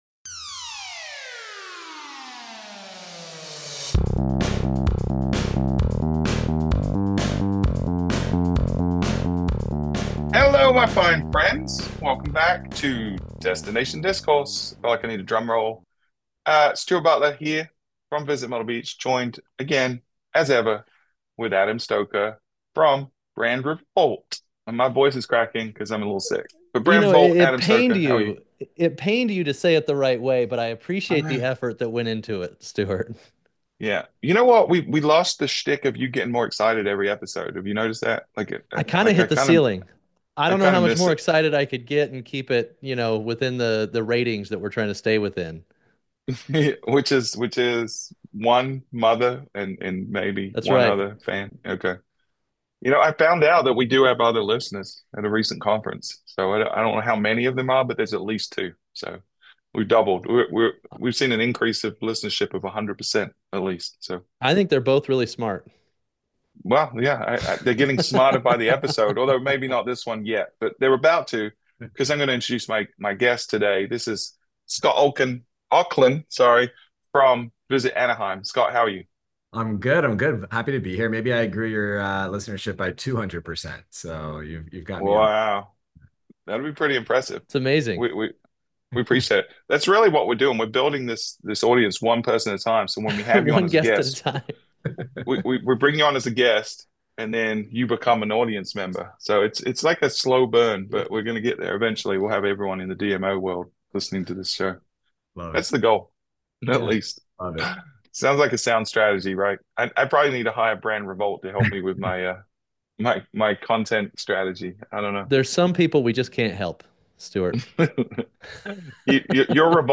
In this episode of Destination Discourse, hosts